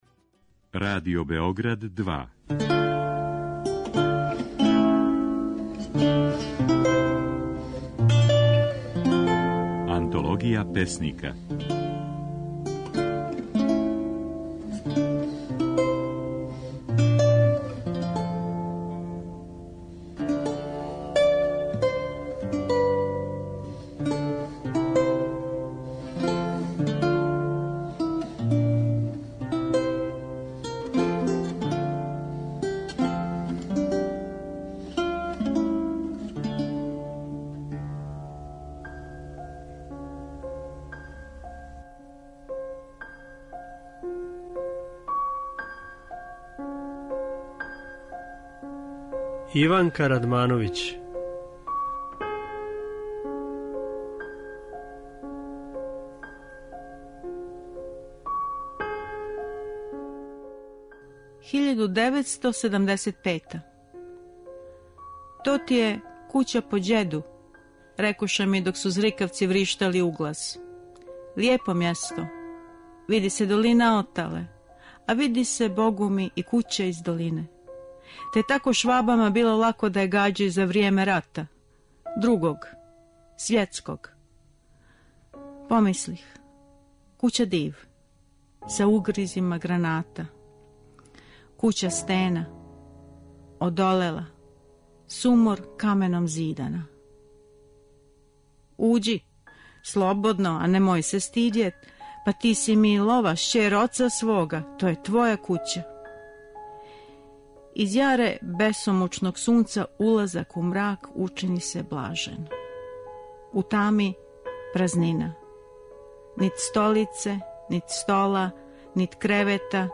Емитујемо снимке на којима своје стихове говоре наши познати песници